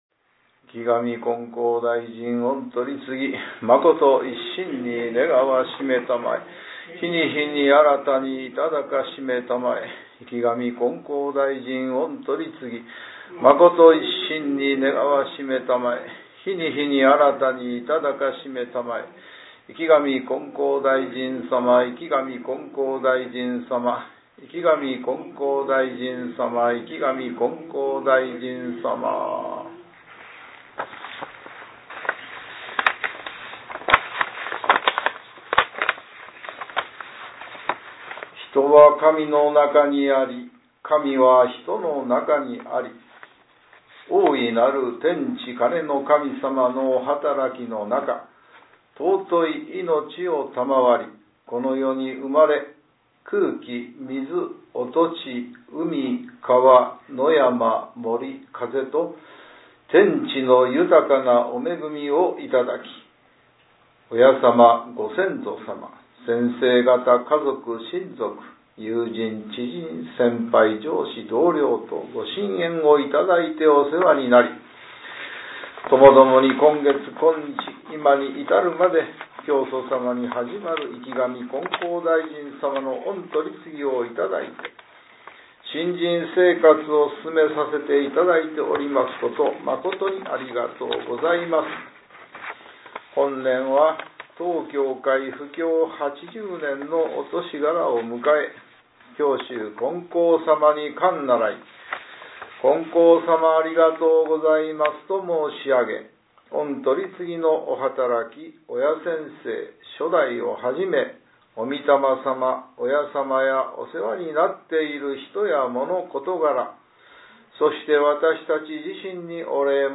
天地金乃神様ご大祭日 祭詞 R4.5.20 | 悩み相談・願い事祈願「こころの宮」
天地金乃神様ご大祭日　祭詞　R4.5.20-1.mp3